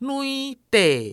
nui deˆ
nui⁺ deˆ